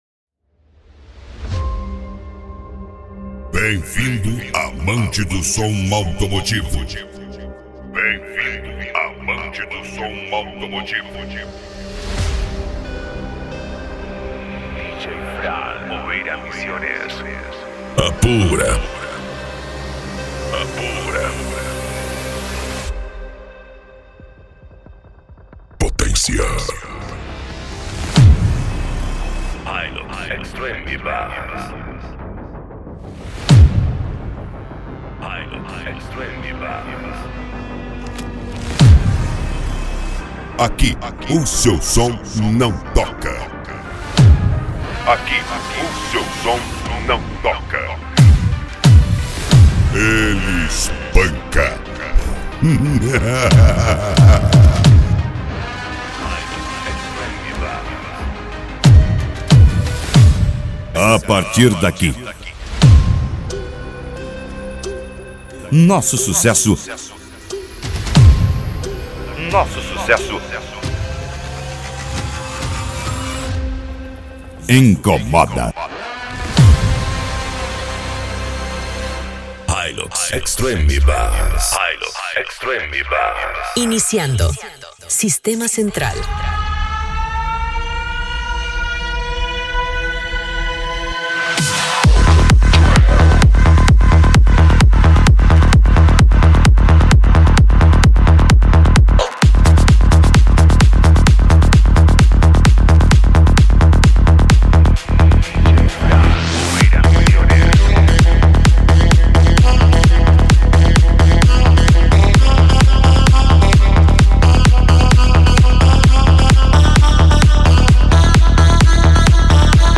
Bass
Eletronica
Remix